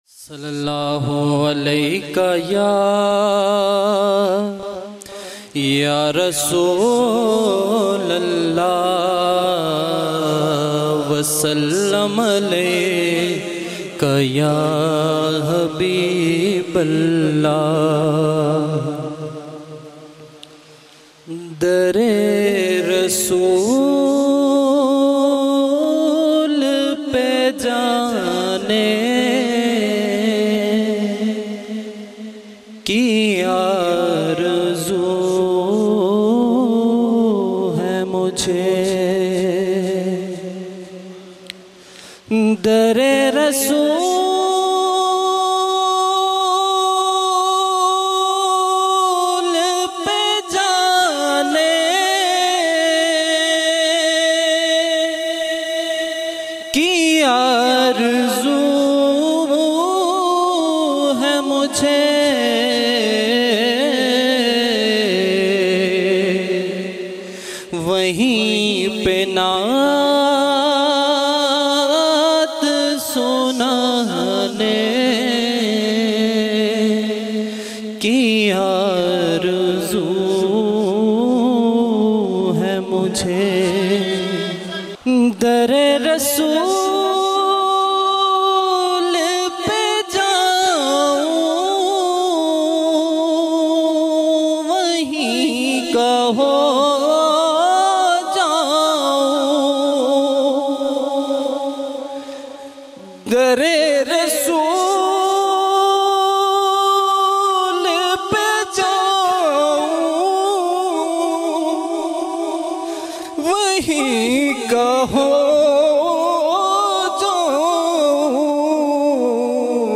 آڈیو نعتیں